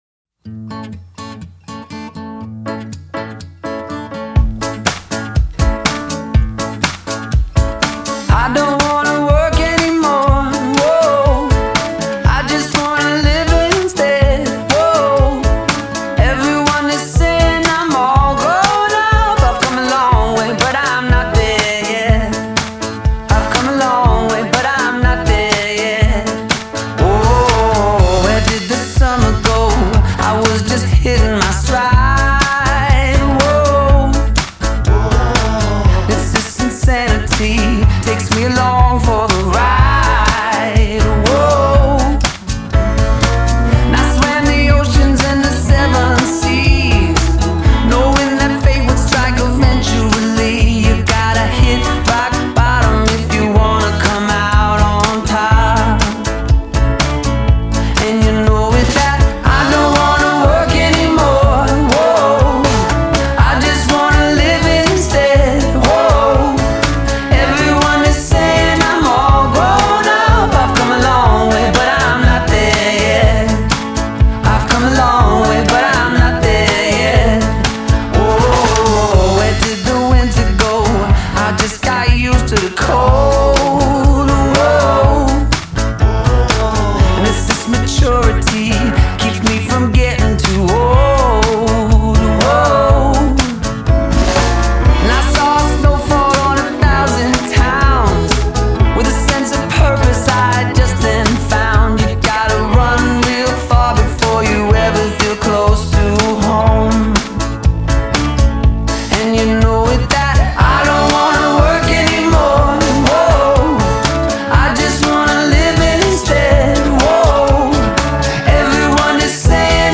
catchy, engaging pop songs
It’s rather slight, but enjoyable stuff.